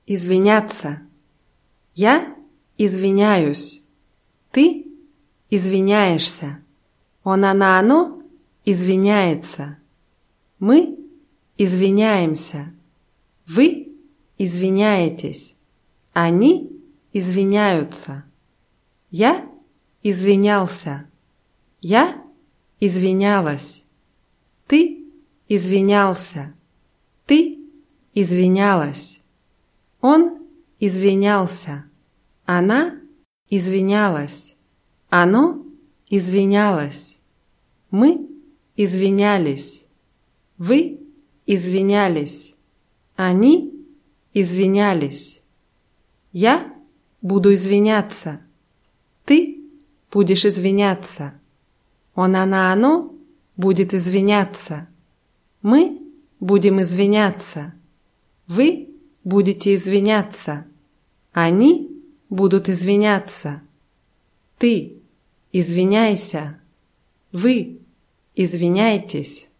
извиняться [izwʲinʲátsa]